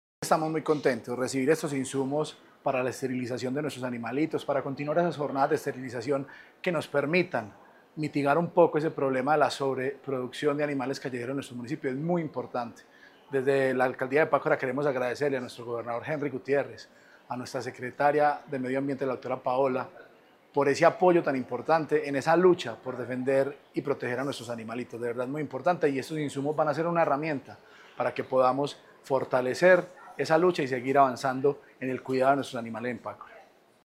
Juan Camilo Isaza, alcalde de Pácora.